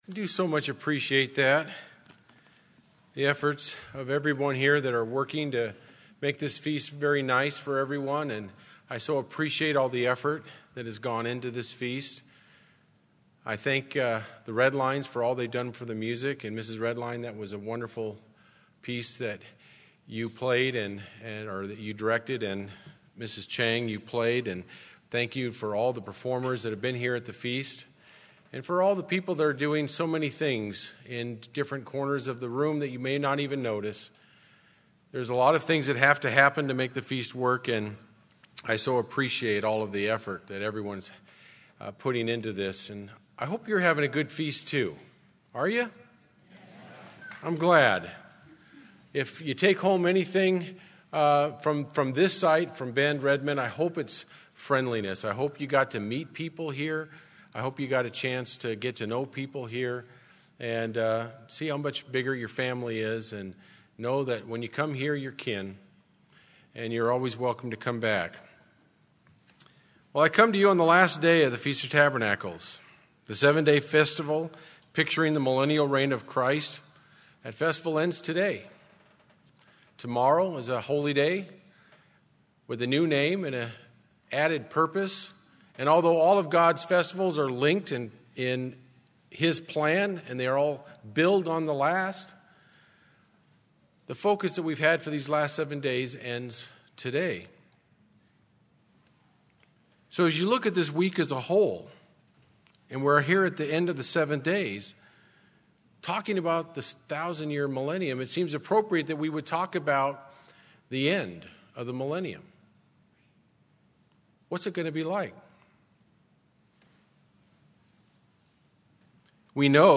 This sermon was given at the Bend-Redmond, Oregon 2018 Feast site.